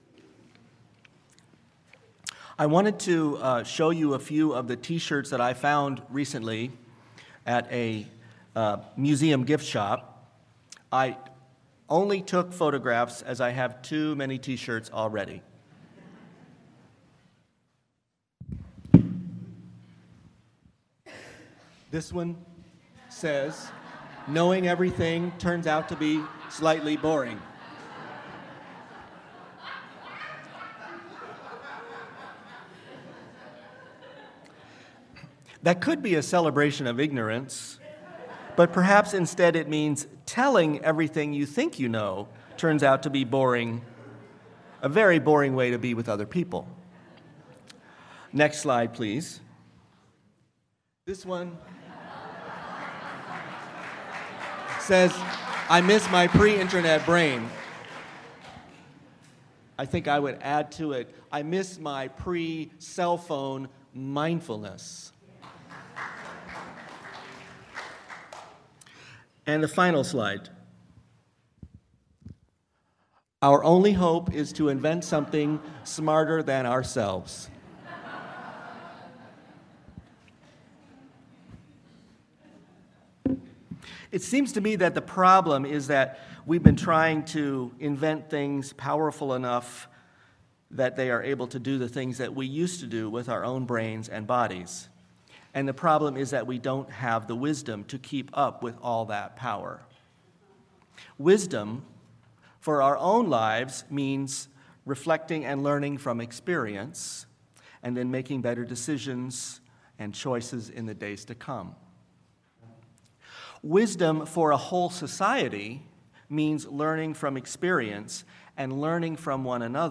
Audio timing notes: Sermon begins at 0:00 (beginning of track.)
Sermon-Tee-Shirt-Spirituality.mp3